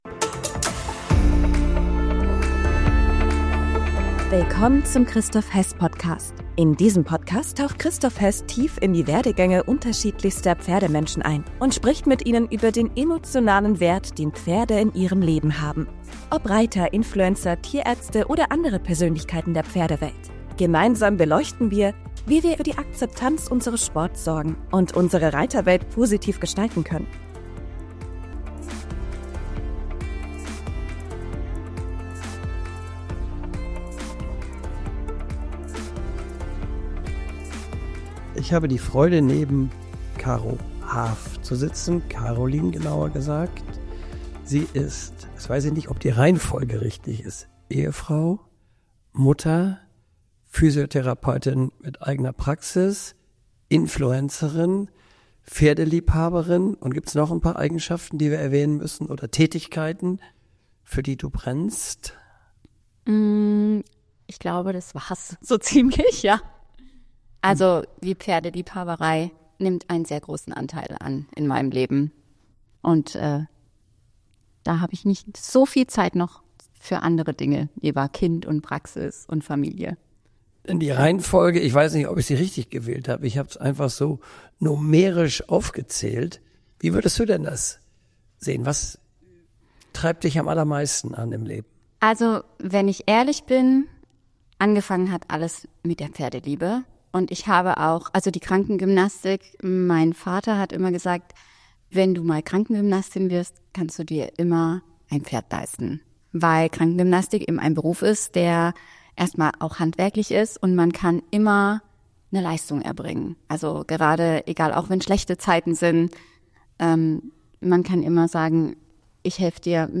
Ein Gespräch über Leidenschaft, Verantwortung und die Kunst, das Leben mit Pferden in Balance zu halten.